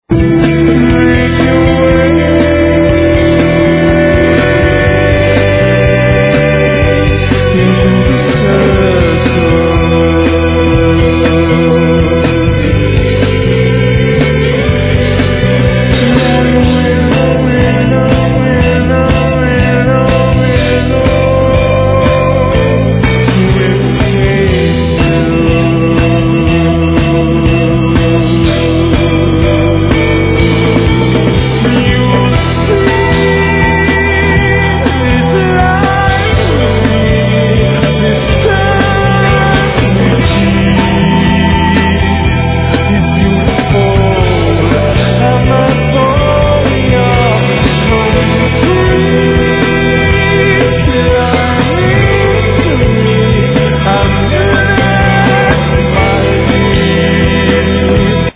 Doumbek, Tar, Riq, Congas, Shakes, Bells
Guitars, Synthes, Piano, Loop, Textures
Vocals
Drum, Loops, Samples
Fender Bass, Synthes, Piano, Shruti box
Trumpet
Flute
Violin
Didgeridoo